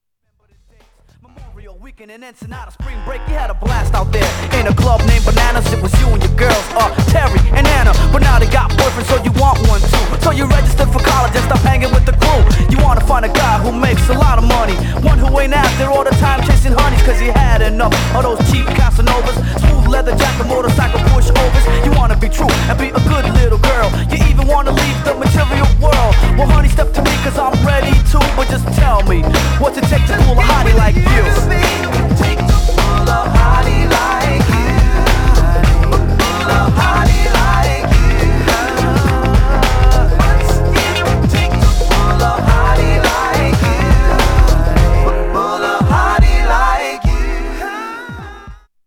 Styl: Hip Hop